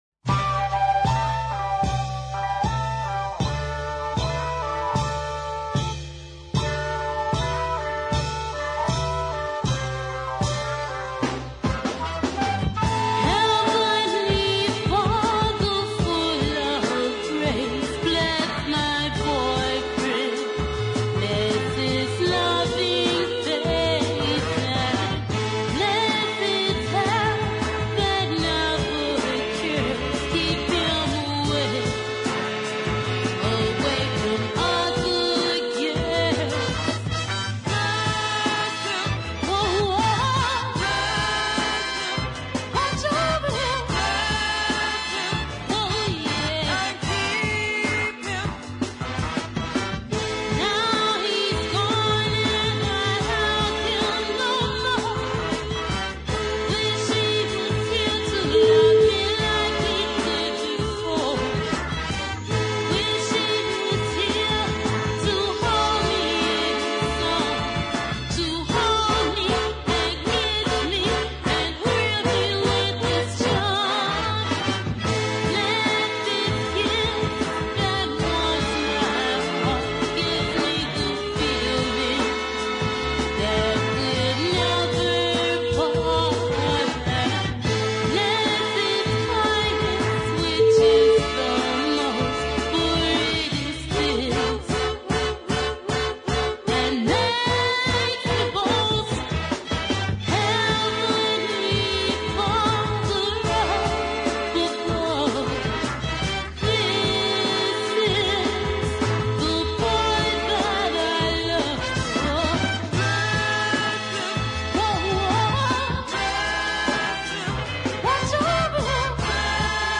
a fine ballad